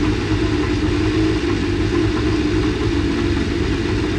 rr3-assets/files/.depot/audio/Vehicles/f1_03/f1_03_idle.wav
f1_03_idle.wav